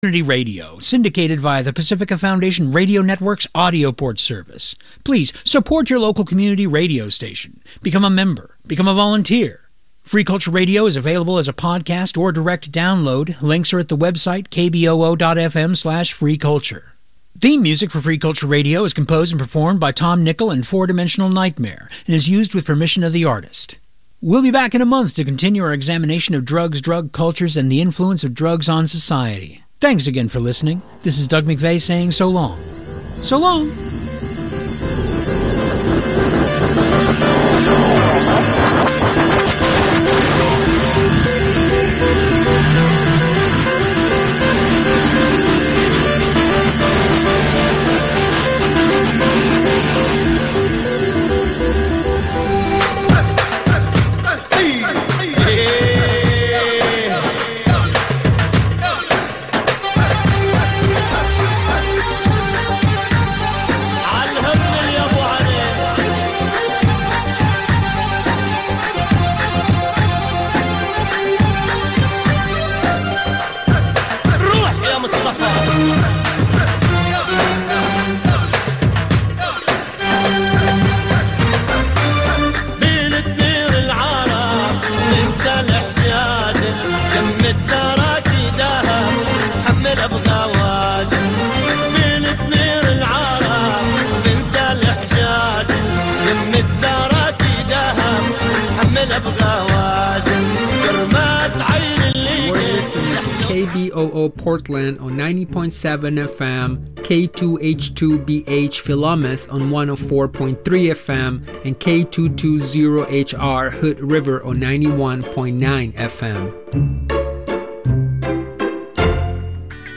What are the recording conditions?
Culture in Motion, LIVE